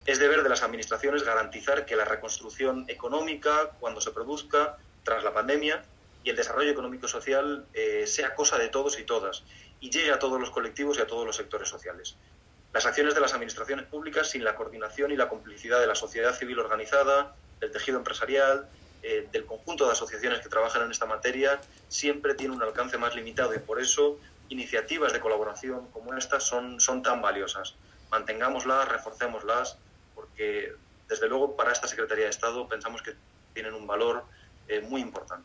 Así lo pusieron de manifiesto en el III Encuentro de Diplomacia para la Inclusión organizado de forma semipresencial, el pasado 25 de noviembre, por el Grupo Social ONCE y la Academia de la Diplomacia, bajo el patrocinio del embajador de Alemania en España, país que ejerce la presidencia semestral del Consejo de la UE.